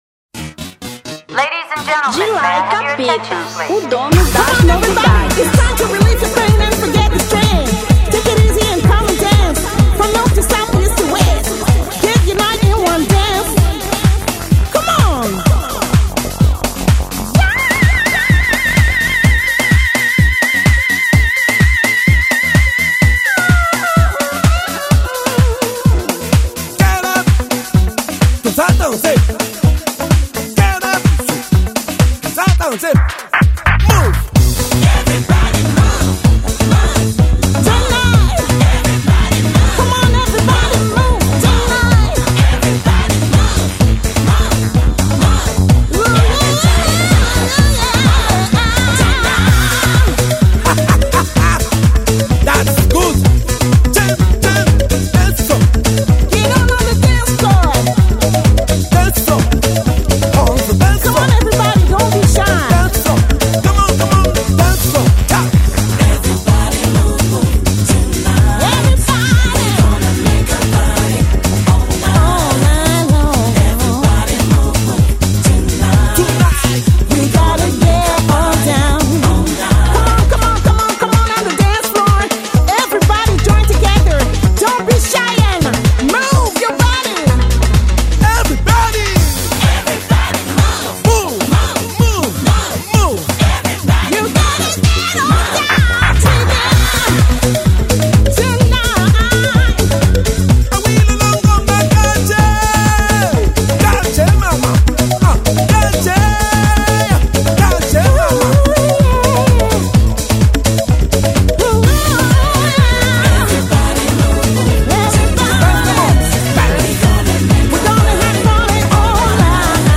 R&B 2003